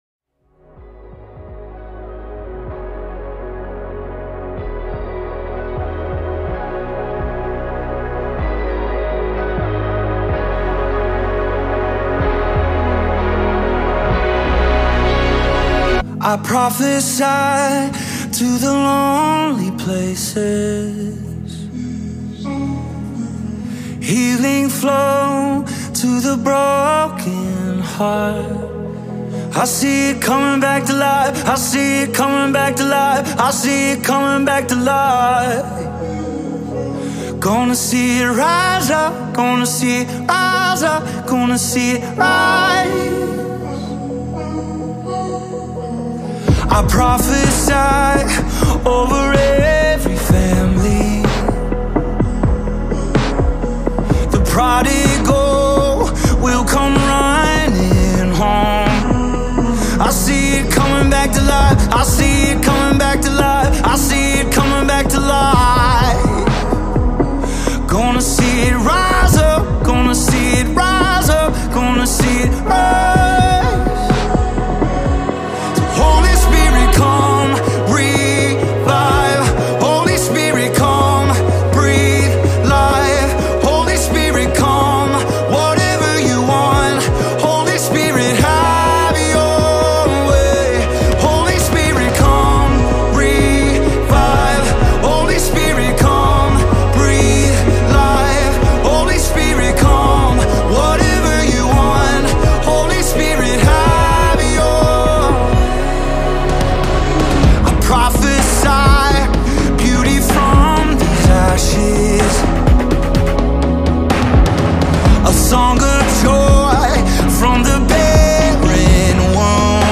Foreign Artists - Gospel Songs Collection